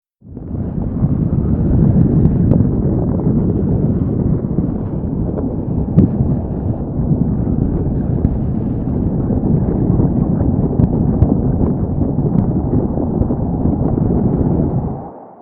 Slow Avalanche Rumble
Slow Avalanche Rumble is a free nature sound effect available for download in MP3 format.
Slow Avalanche Rumble.mp3